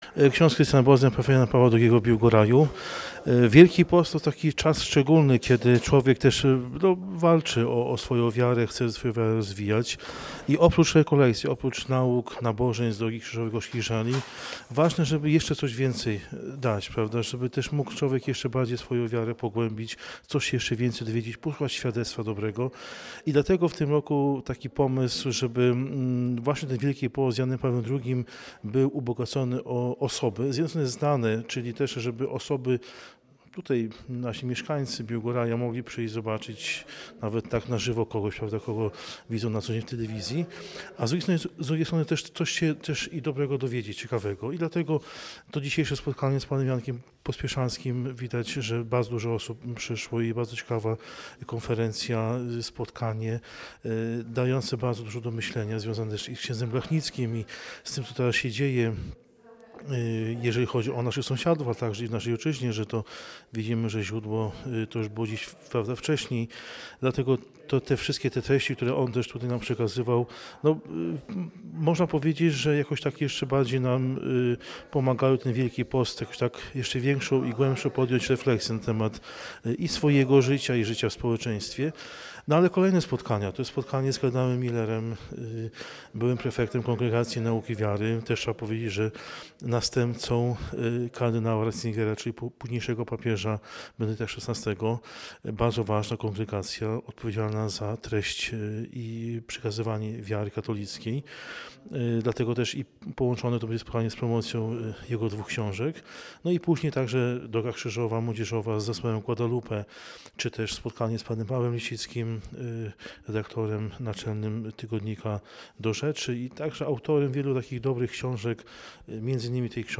Wiadomości Biłgorajskie[gallery size="full" bgs_gallery_type="slider" ids="616624,616616,616632,616639,616640,616617,616638,616637,616620,616636,616622,616635,616621,616634,616618,616633,616614,616615,616619,616627,616625,616626,616629"] Wydarzenie zostało przygotowane przez seniorów jako inicjatywa obywatelska.